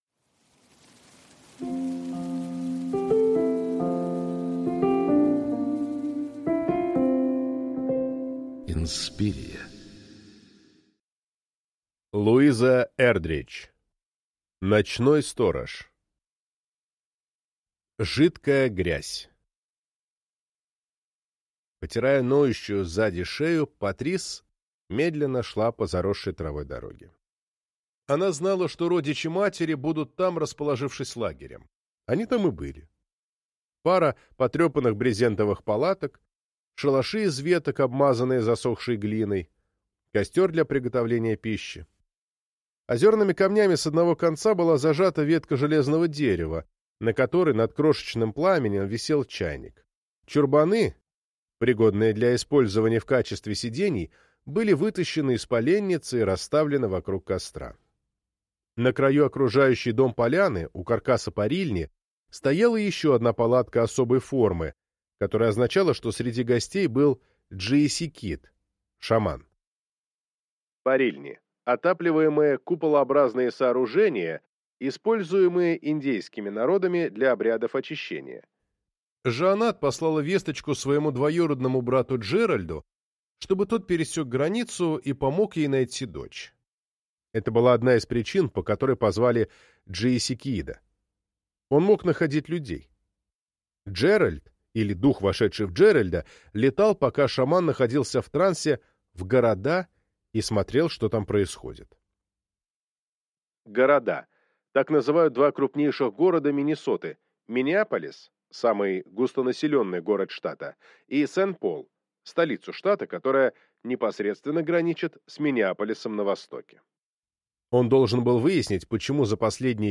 Аудиокнига Ночной сторож | Библиотека аудиокниг